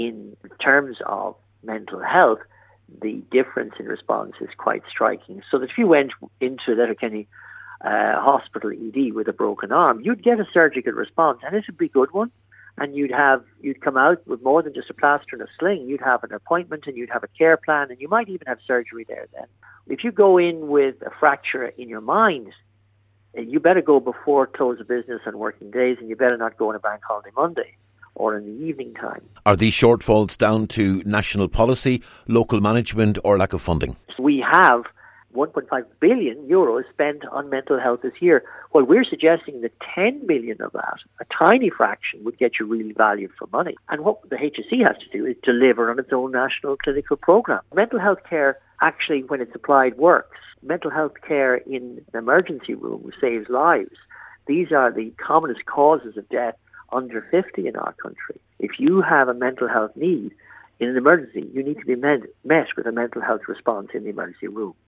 Dr Lucey told Highland Radio News the situation in Letterkenny University Hospital is typical of Model 3 hospitals across the country in terms of the disparity between the responses to physical issues as opposed to mental ones………..